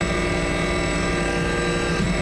railgun-turret-rotation-loop.ogg